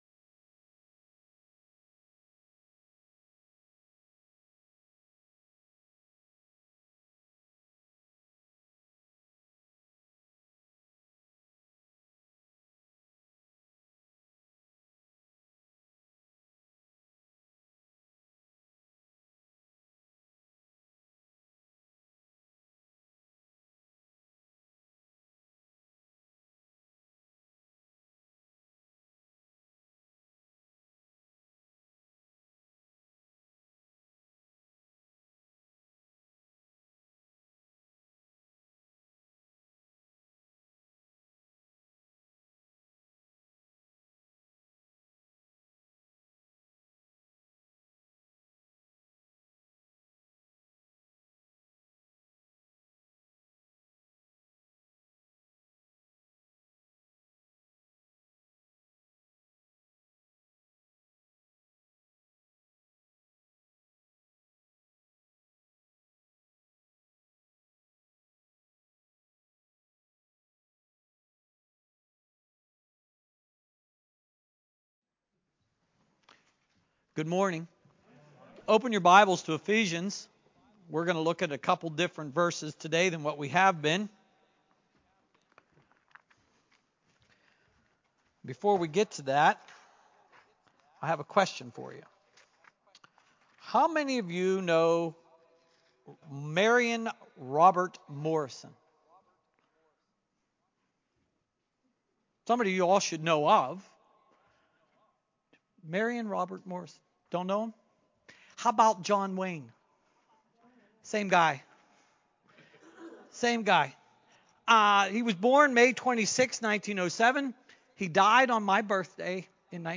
Faith Prayer Part 1 Sermon
Faith-Prayer-Part-1-Sermon-Audio-CD.mp3